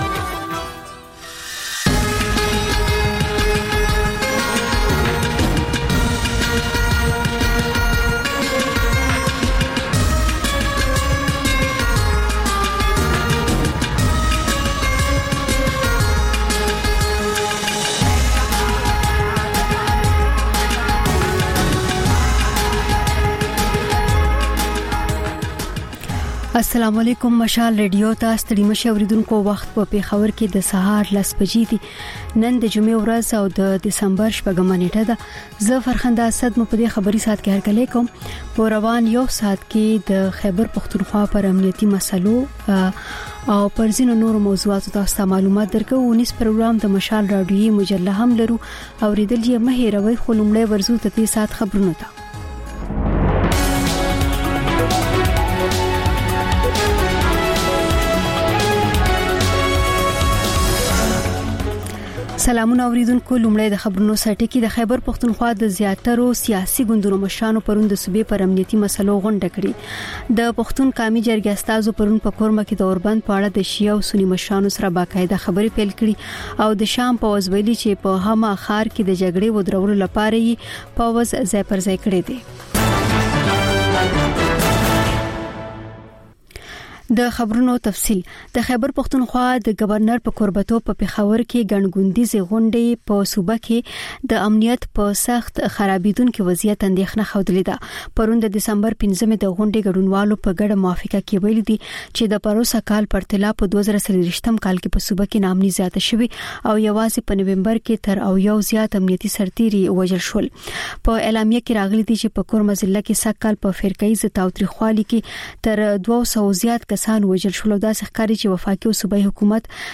په دې خپرونه کې تر خبرونو وروسته بېلا بېل رپورټونه، شننې او تبصرې اورېدای شئ. د خپرونې په وروستۍ نیمايي کې اکثر یوه اوونیزه خپرونه خپرېږي.